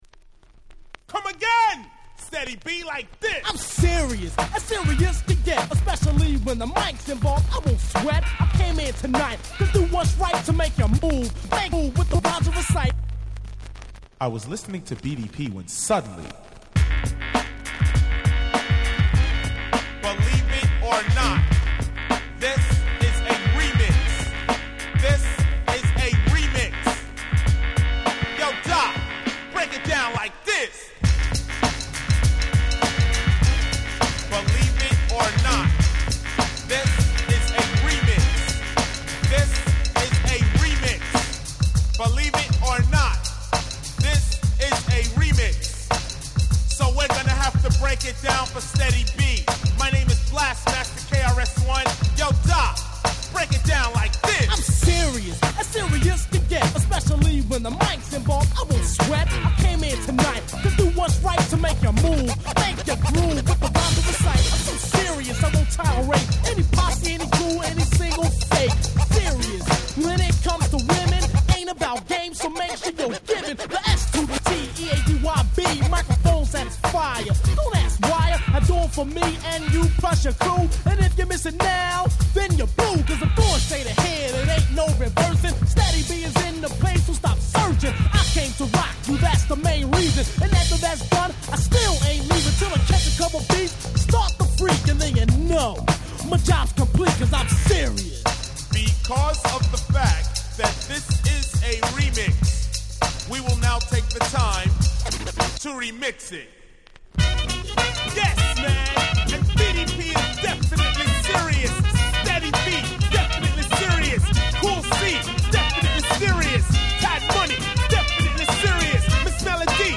89' Smash Hit Hip Hop !!